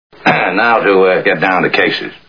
Caine Mutiny Movie Sound Bites